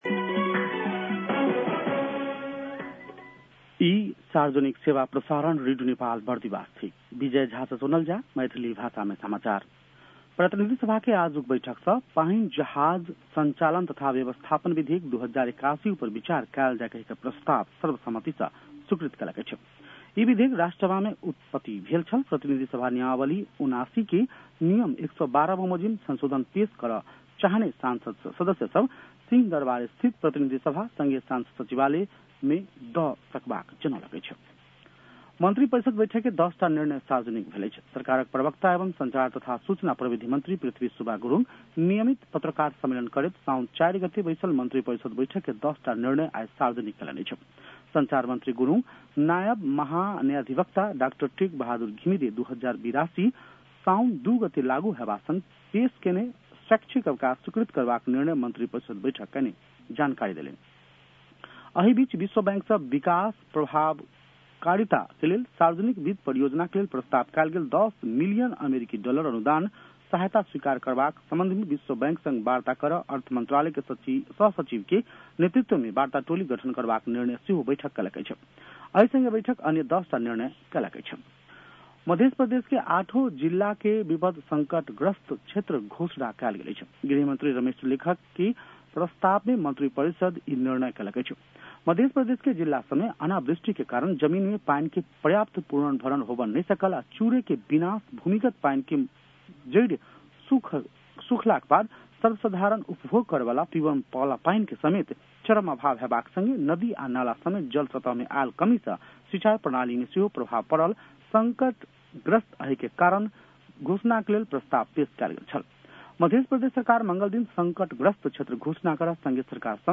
मैथिली भाषामा समाचार : ७ साउन , २०८२